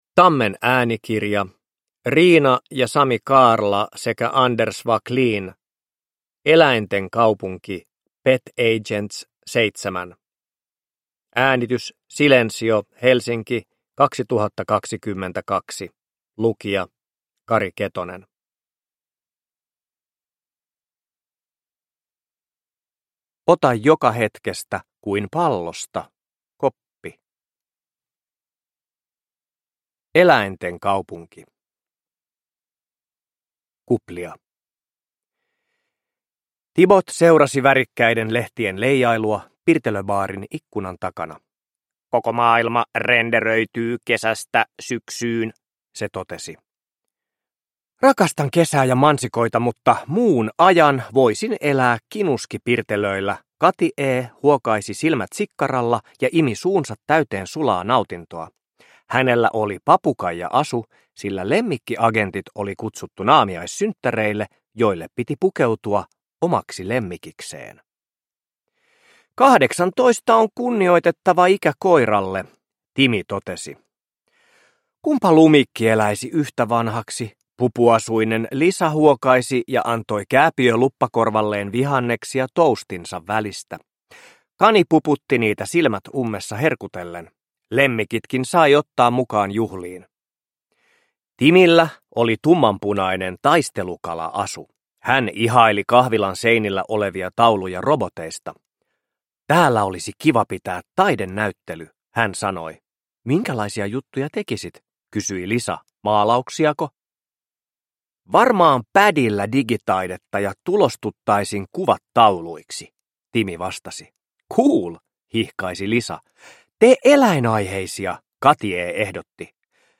Eläinten kaupunki. Pet Agents 7 – Ljudbok – Laddas ner